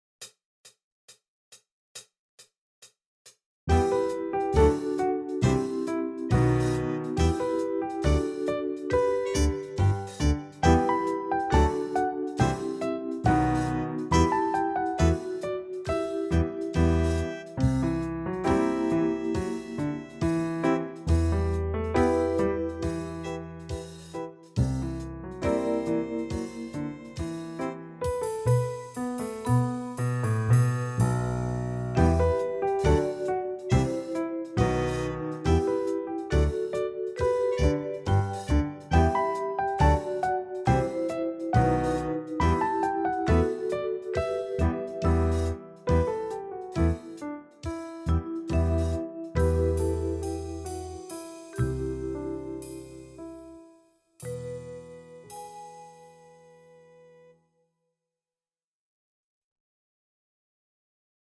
Voicing: Piano Method